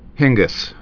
(hĭnggəs), Martina Born 1980.